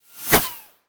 bullet_flyby_03.wav